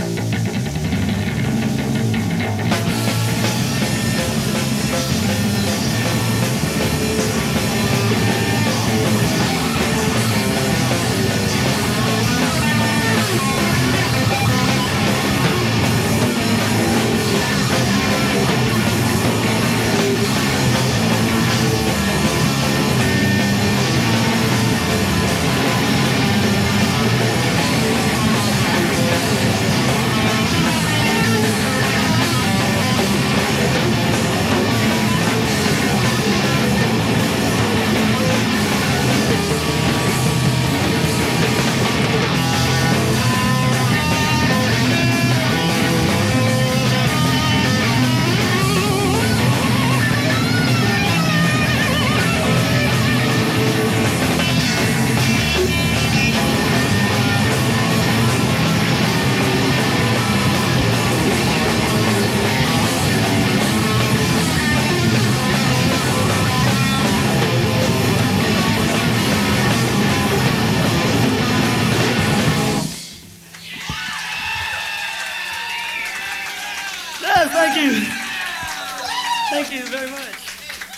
when the sound was raw